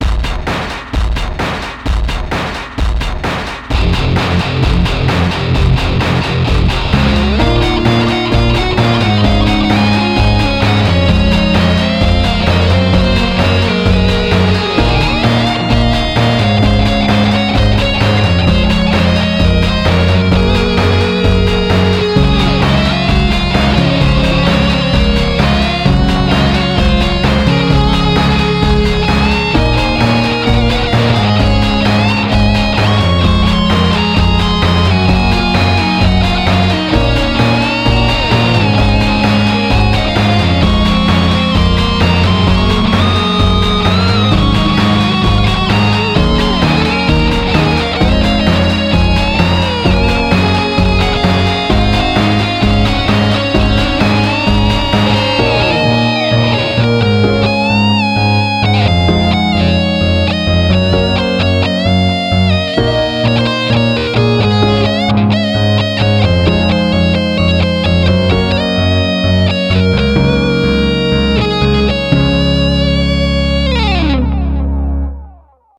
It was originally performed at a small show at HLG live, and was improvised. Certain things have been changed from the original to maintain the "live" intensity.